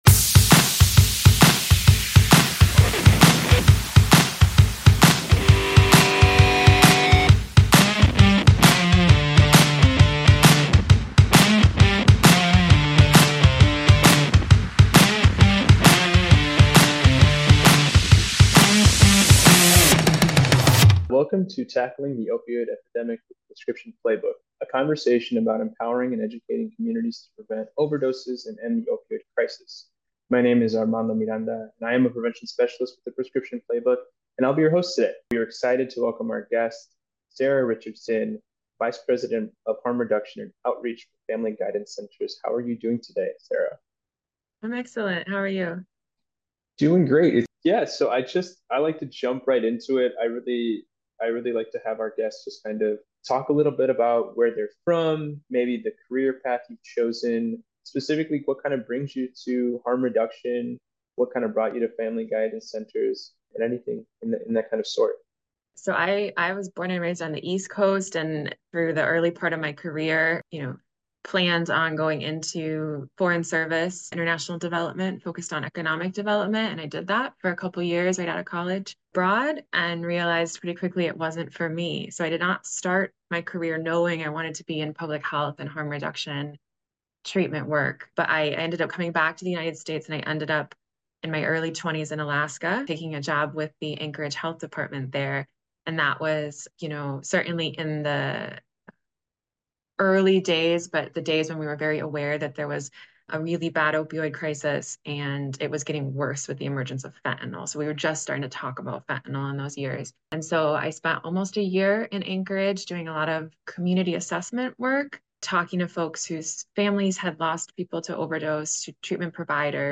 Don’t miss this insightful conversation on how local, coordinated efforts can make a lasting impact.